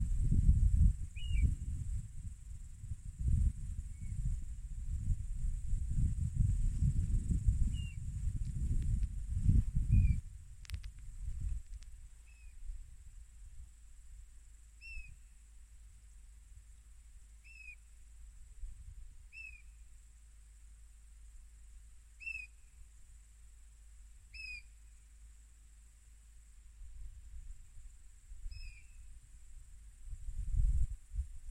Putni -> Bridējputni ->
Dzeltenais tārtiņš, Pluvialis apricaria
Administratīvā teritorijaSalacgrīvas novads
StatussDzirdēta balss, saucieni